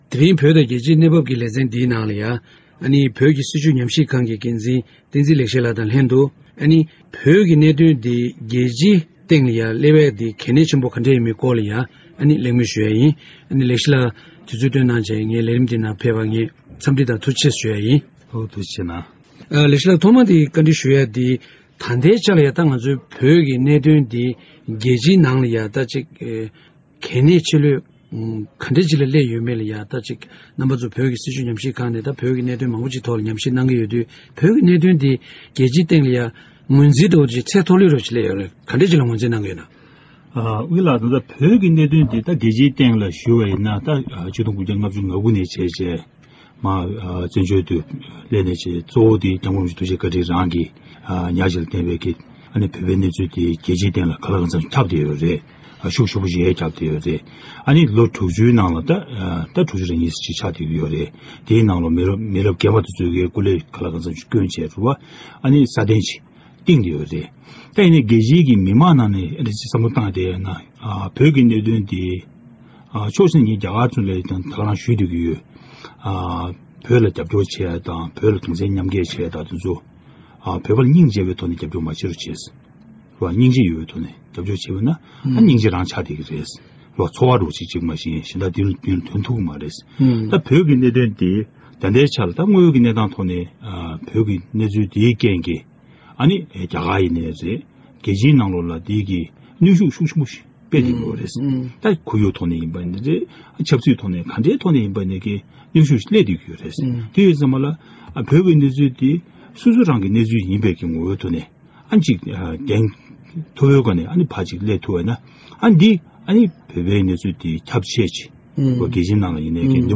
བོད་ཀྱི་གནད་དོན་རྒྱལ་སྤྱའི་སྟེང་སླེབ་ཐབས་དང་འབྲེལ་བའི་གལ་གནད་སྐོར་གླེང་མོལ།